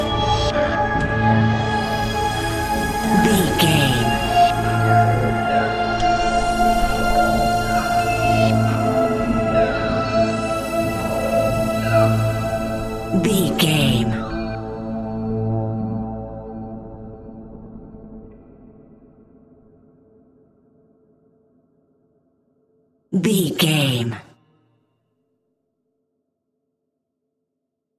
Aeolian/Minor
tension
ominous
dark
eerie
piano
percussion
strings
synthesiser
Horror Synths
atmospheres